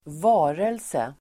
Ladda ner uttalet
varelse substantiv, being Uttal: [²v'a:relse] Böjningar: varelsen, varelser Synonymer: väsen Definition: något som lever, levande väsen Exempel: mänsklig varelse (human being), levande varelse (living creature)